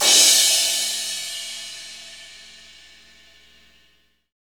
Index of /90_sSampleCDs/Sound & Vision - Gigapack I CD 1 (Roland)/CYM_K-CRASH st/CYM_K-Crash st 2
CYM CRA380EL.wav